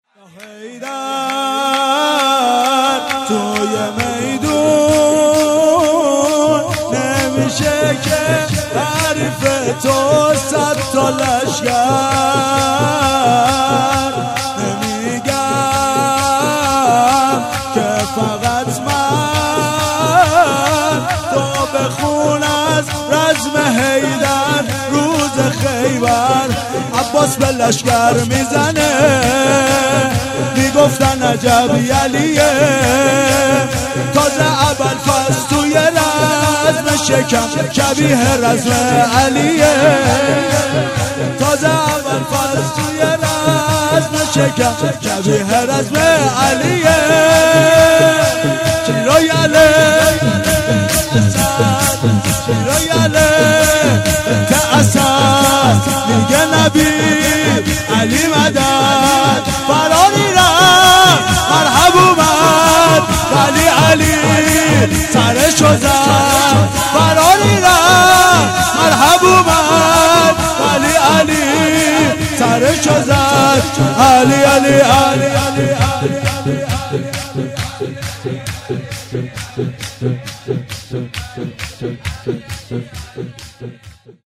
جشن ولادت حضرت زهرا(س)- جمعه 18 اسفند
0 0 شور